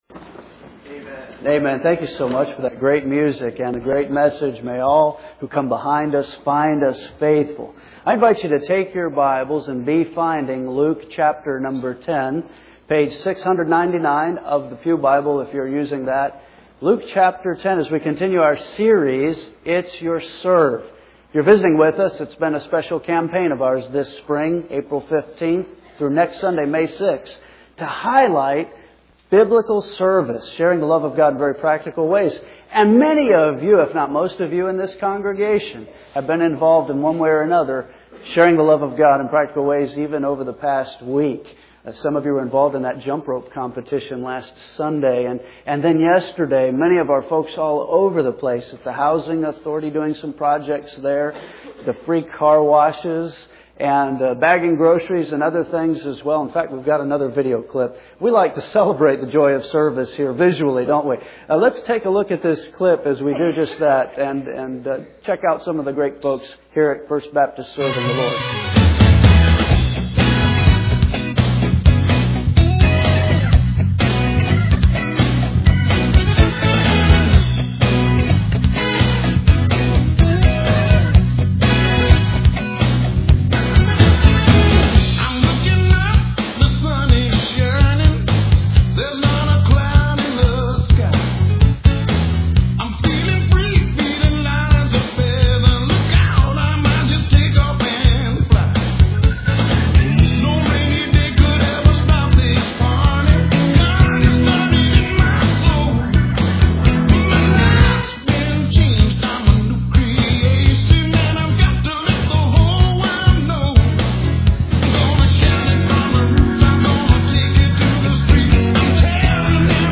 Today, we return to “Stereo Preaching.”